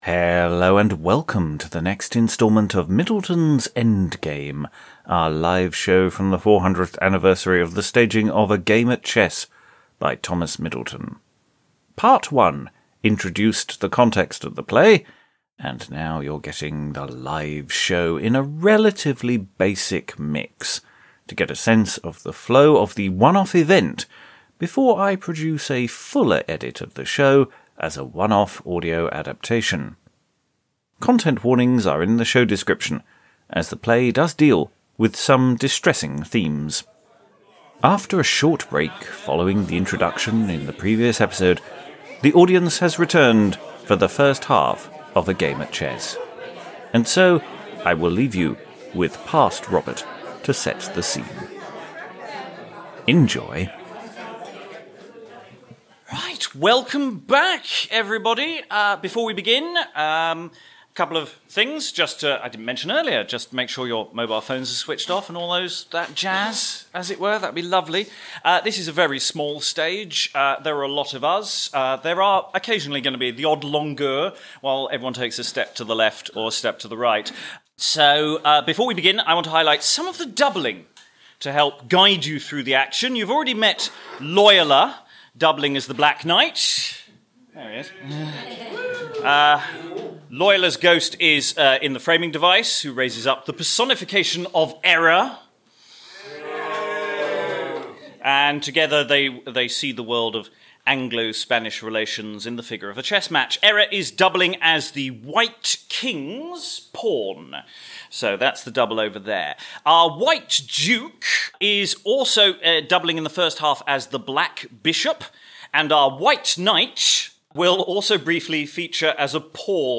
Thomas Middleton's scandalous play, performed live for the 400th anniversary.
It is a relatively simple mix of the play, a fuller mix with extra bells and whistles will follow anon.